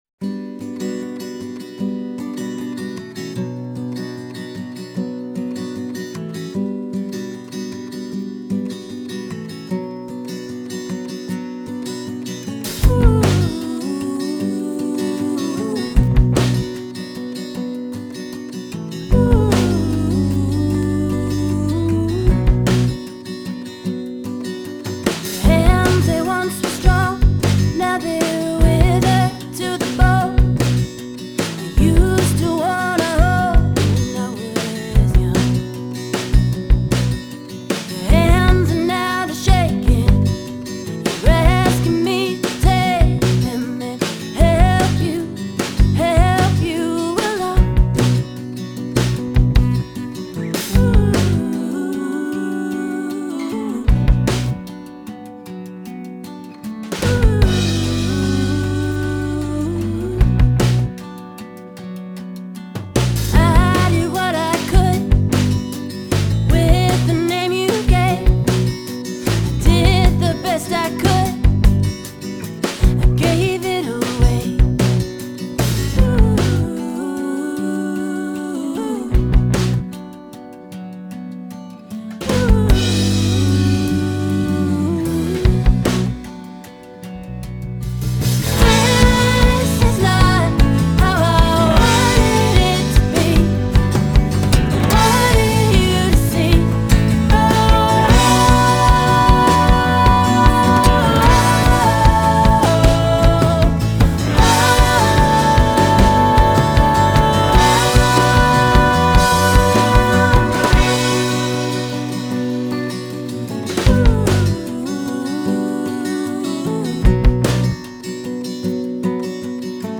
Genre: Folk Pop, Americana, Singer-Songwriter